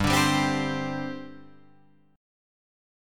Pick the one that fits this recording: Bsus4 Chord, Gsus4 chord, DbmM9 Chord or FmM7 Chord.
Gsus4 chord